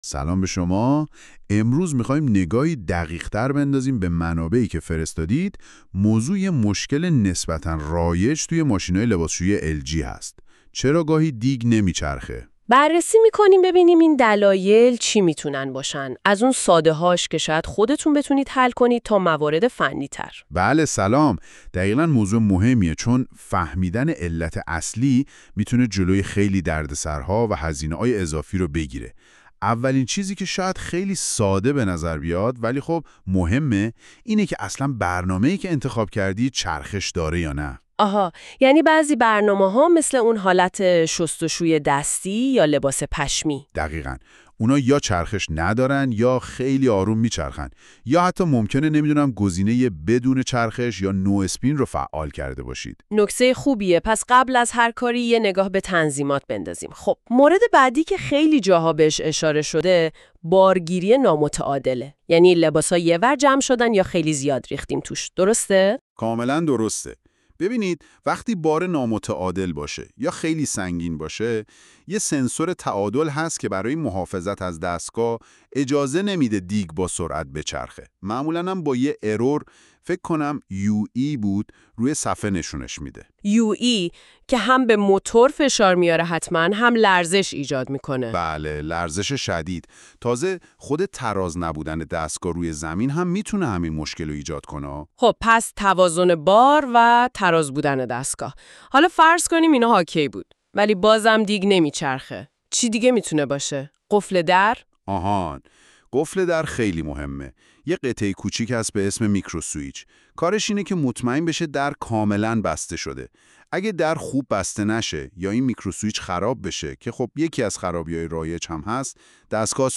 این پادکست توسط تیم محتوای تعمیر۲۴ تهیه شده و با ابزار Notebook LM (نوت‌بوک ال‌اِم) تولید و خلاصه‌سازی شده است تا مراحل عیب‌یابی نچرخیدن دیگ لباسشویی ال جی را به‌صورت کوتاه و عملی ارائه دهد.